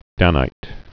(dănīt) Bible